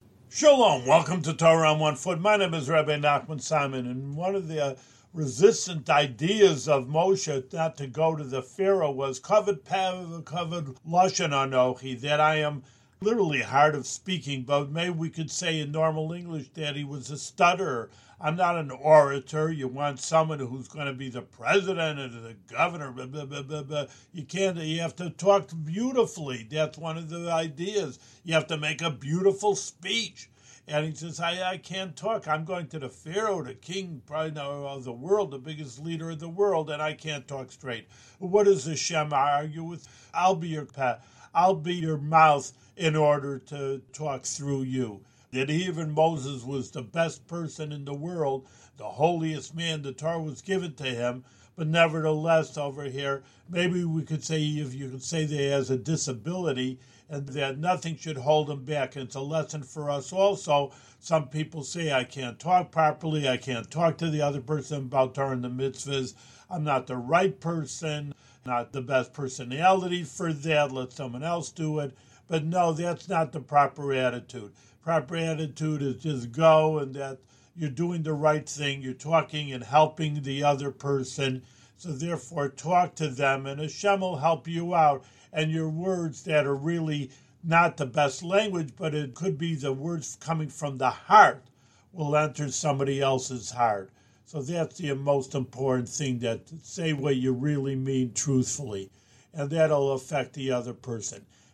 One-minute audio lessons on special points from weekly Torah readings in the Book of Exodus.